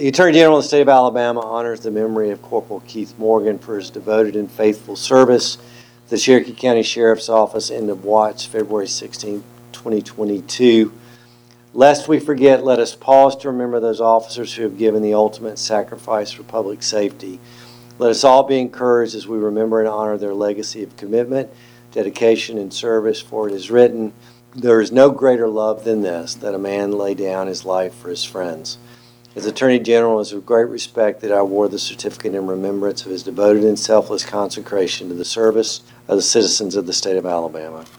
Attorney General Attends Fallen Officer Memorial Service In Cherokee County
Attorney General Marshall read the commendation from his office.